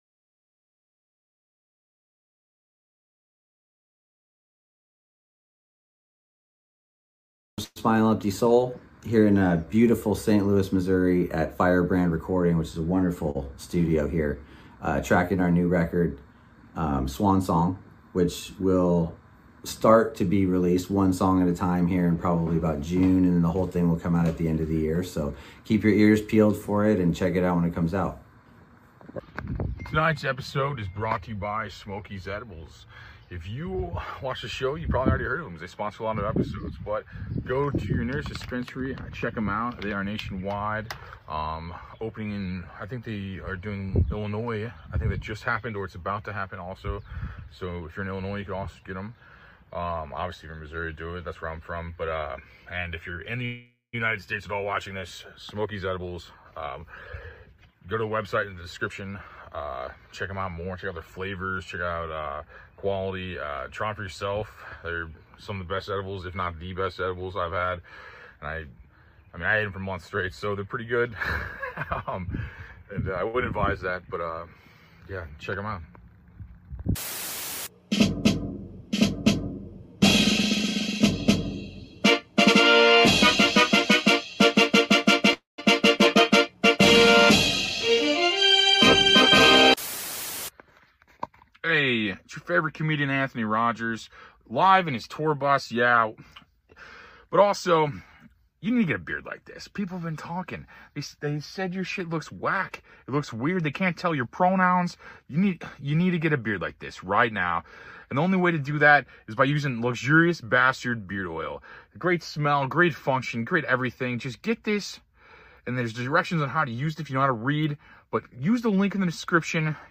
Episode 295 - Joe Exotic Calling in from Prison